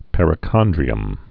(pĕrĭ-kŏndrē-əm)